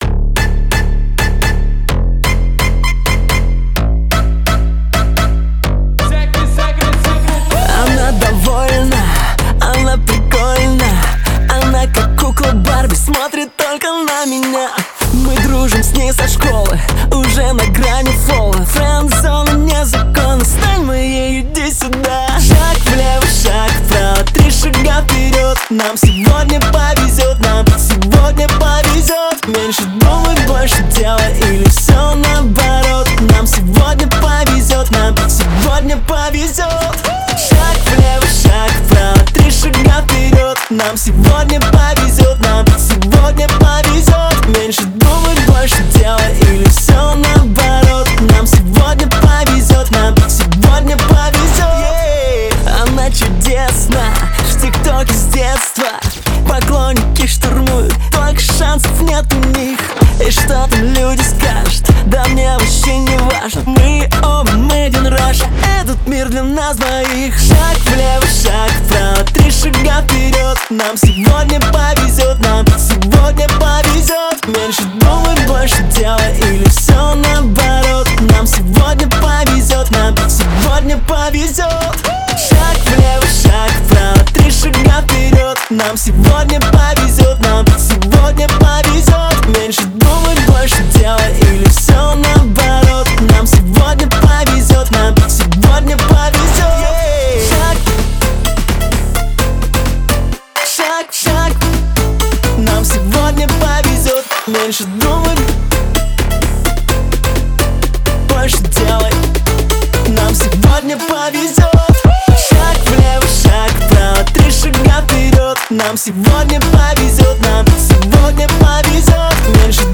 Русские песни